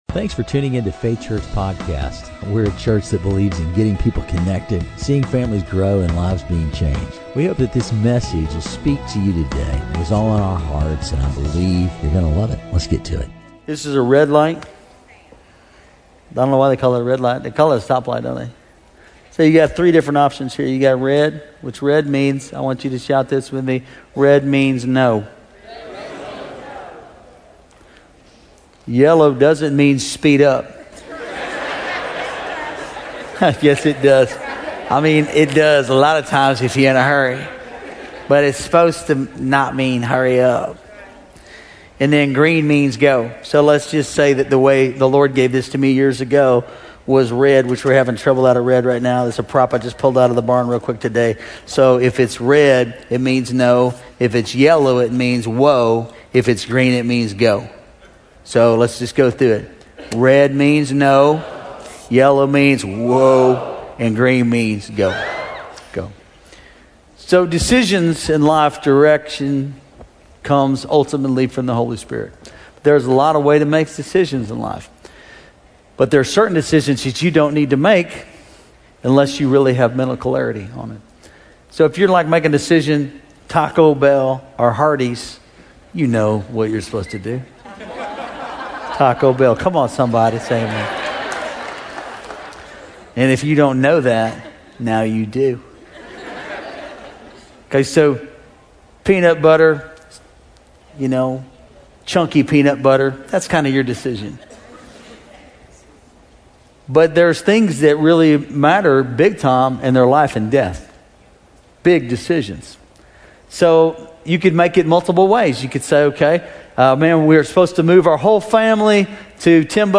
GoOrNoSermon.mp3